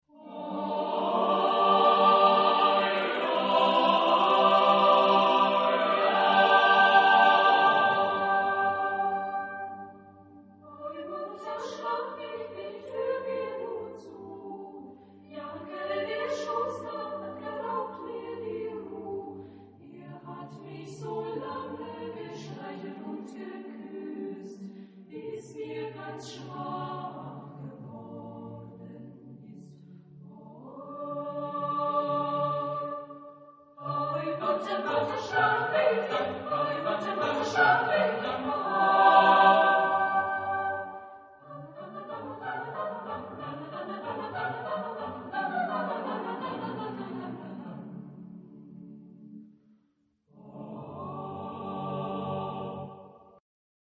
Genre-Style-Form: Folk music ; Partsong ; Secular
Type of Choir: SSAATTBB  (8 mixed voices )
Tonality: G minor
Origin: Eastern Europe